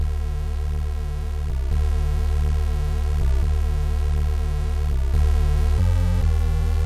蟋蟀的噪音
描述：蟋蟀在晚上制造噪音。
标签： 夜晚 叽叽喳喳 黑暗 bug 动物 蟋蟀 噪声
声道单声道